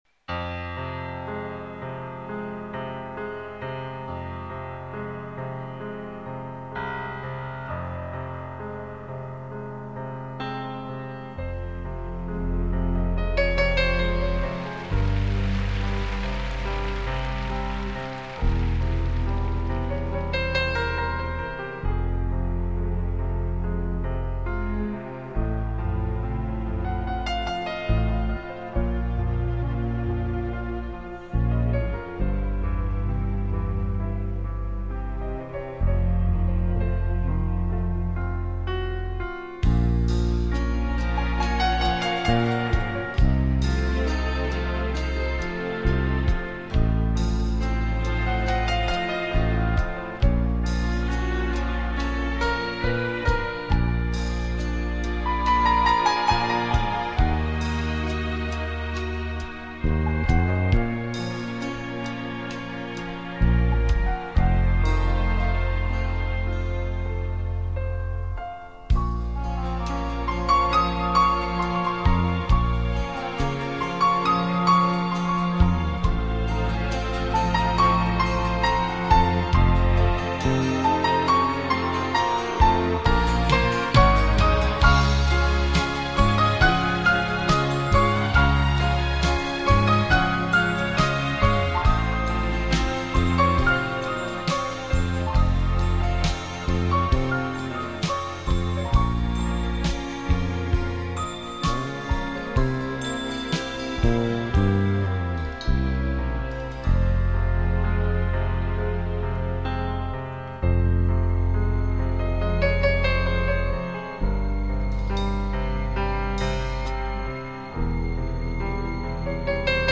纯音乐